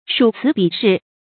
屬辭比事 注音： ㄕㄨˇ ㄘㄧˊ ㄅㄧˇ ㄕㄧˋ 讀音讀法： 意思解釋： 原指連綴文辭，排比事實，記載歷史。